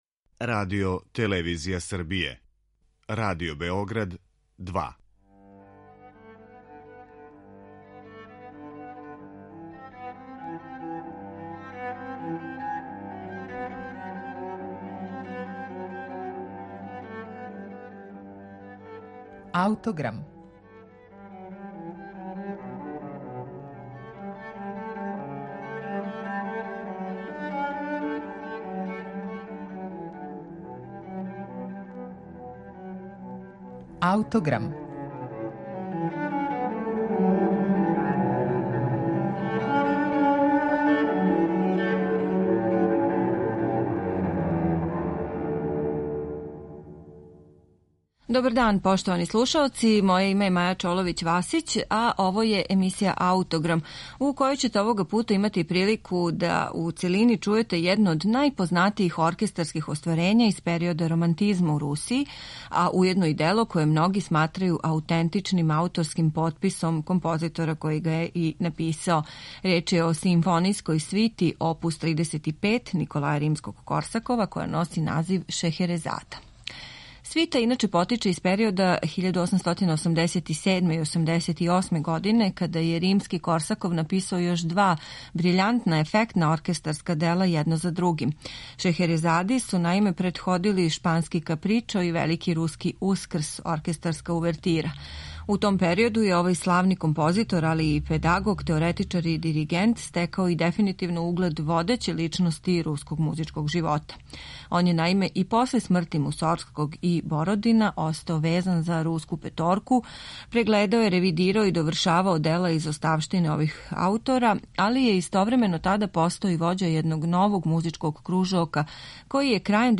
симфонијској свити